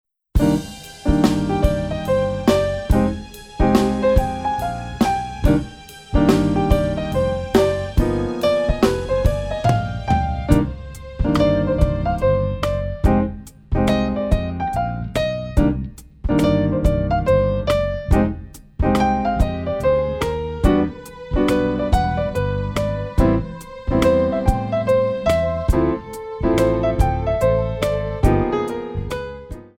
Modern / Contemporary
8 bar intro
jazz waltz